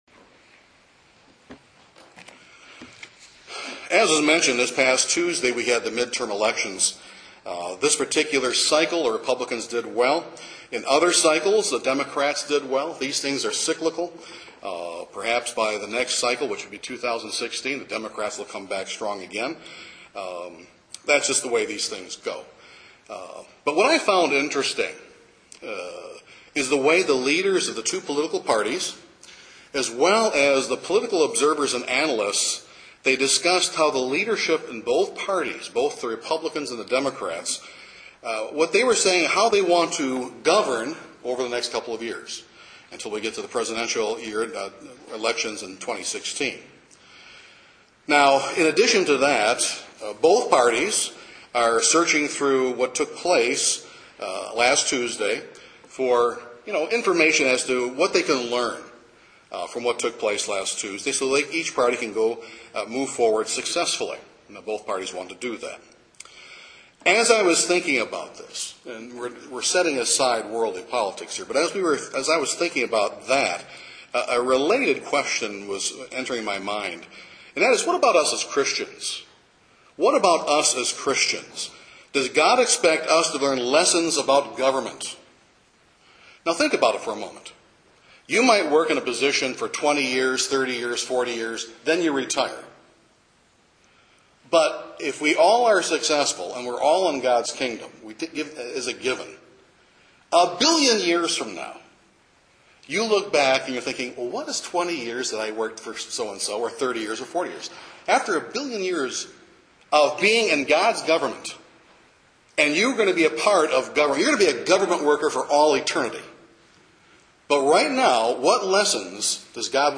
This sermon examines seven key lessons God wants us to learn prior to our career of ruling in the Kingdom.